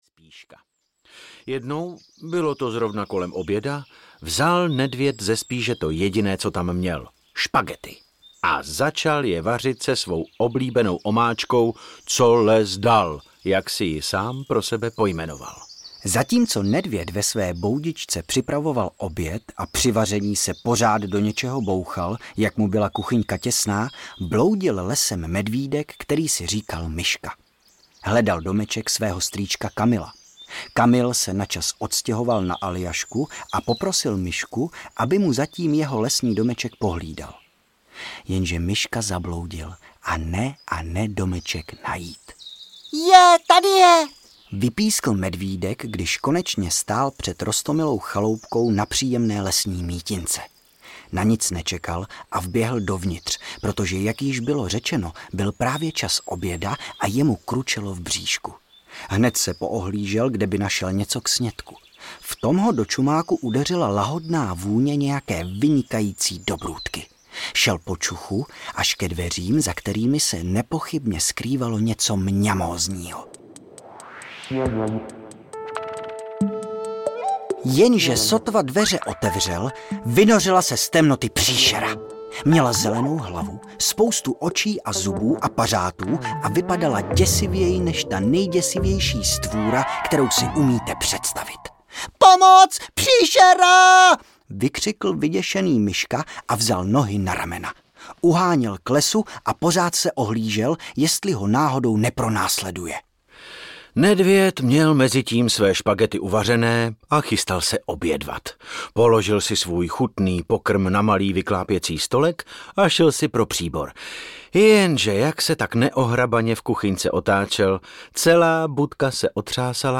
Mlsné medvědí příběhy audiokniha
Ukázka z knihy
• InterpretDavid Novotný, Lukáš Příkazký